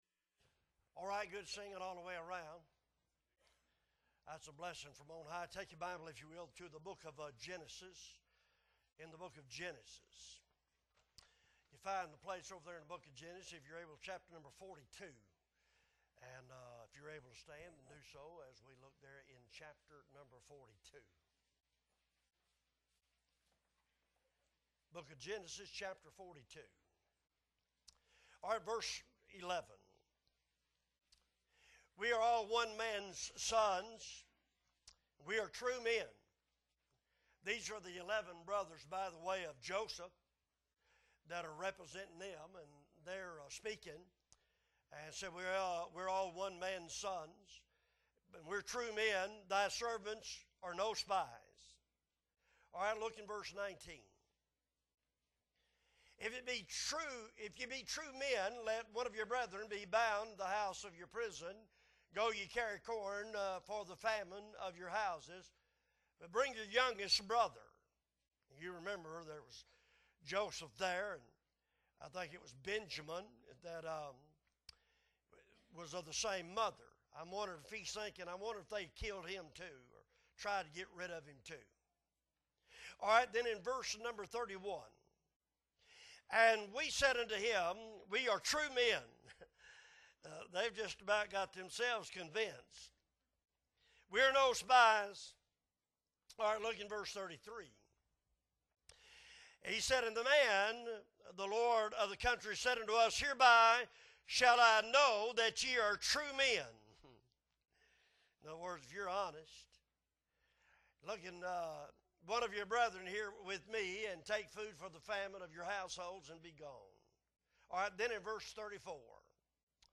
August 24, 2022 Wednesday Night Service - Appleby Baptist Church